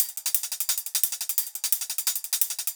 Hat loops (21).wav